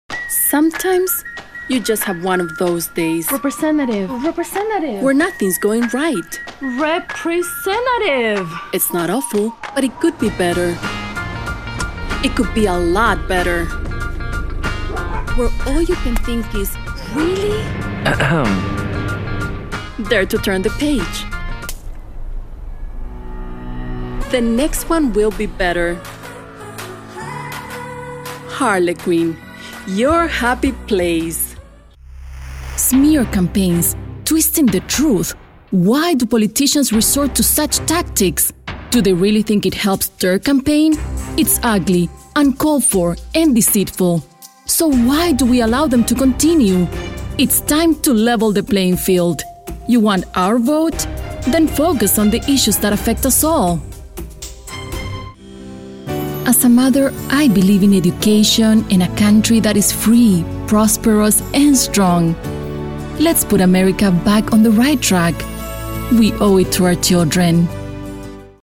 Female
Approachable, Assured, Bright, Bubbly, Confident, Conversational, Cool, Corporate, Energetic, Engaging, Friendly, Funny, Natural, Reassuring, Soft, Upbeat, Warm
Neutral Spanish, Chilean (native), Accented English
Home studio with source connect.
Voice reels
Microphone: AKG P220